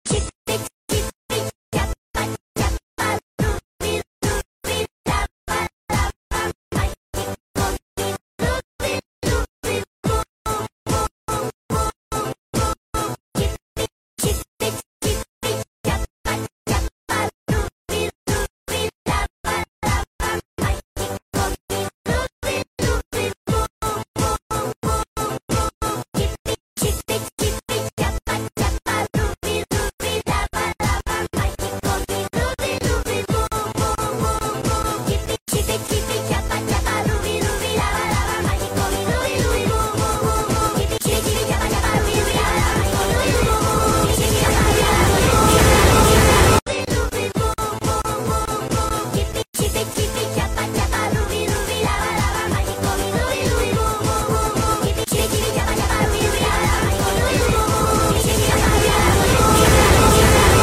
which sounds is playing bouncing